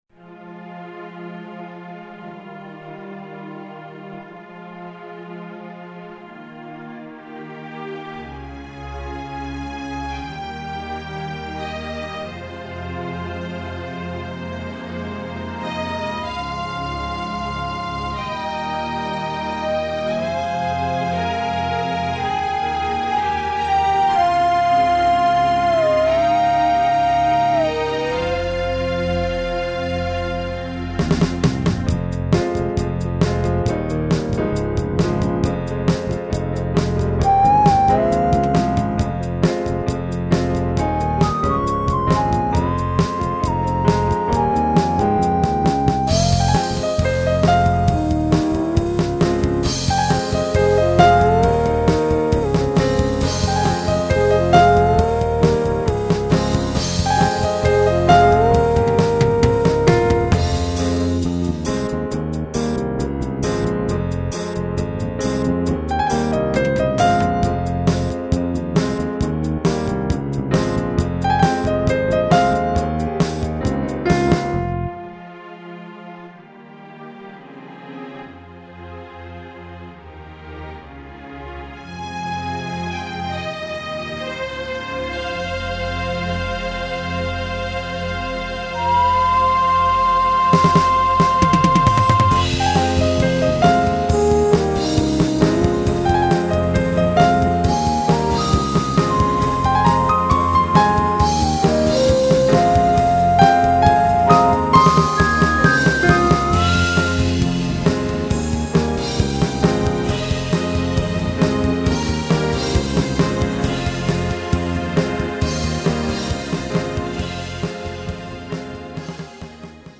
The music here is just some silliness that I played off the top of my head. The arrangement is... quite unlike any of the previous entries.
This is was made completely for practice, to get the hang of arranging stuff and using the tools.
I should get some props for that bassline, at least.
I'll write about it later. multitrack practice (2:00, 1880Kb)